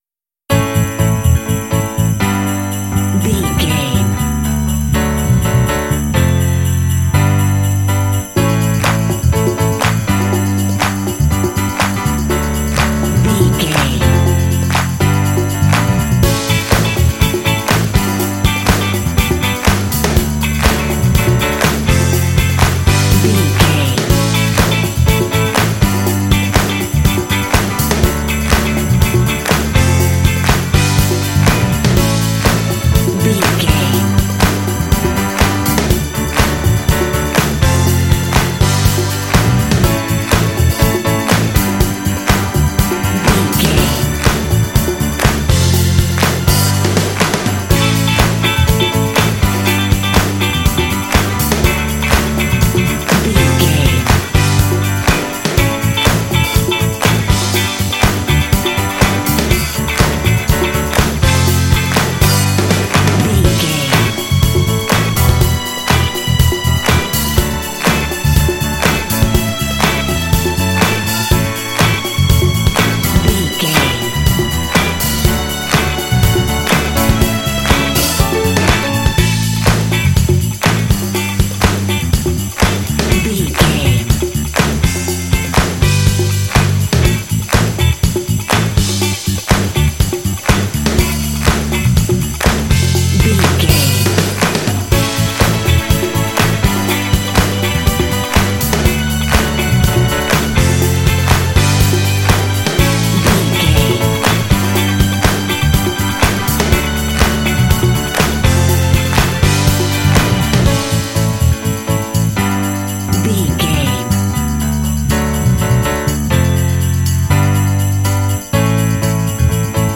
Mixolydian
driving
bouncy
energetic
cheerful/happy
groovy
drums
bass guitar
electric organ
electric guitar
piano
strings
big band